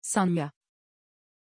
Aussprache von Sanya
pronunciation-sanya-tr.mp3